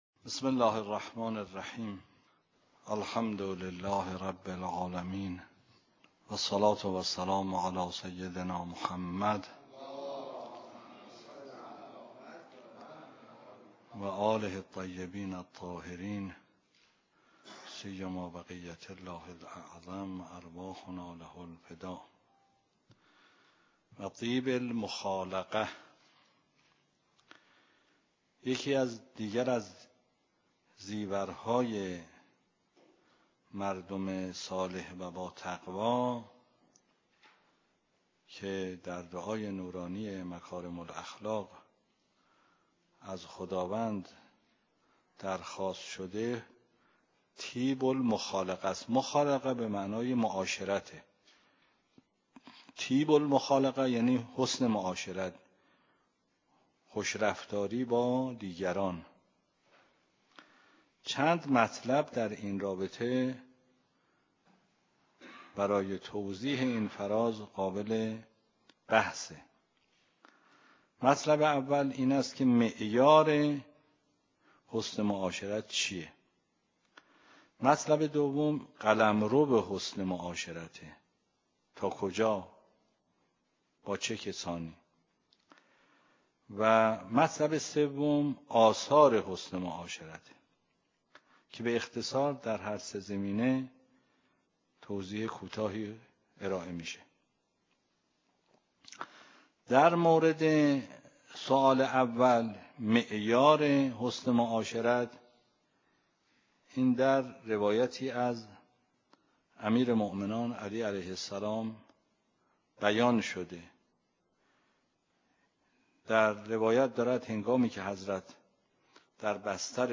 درس خارج فقه مبحث حج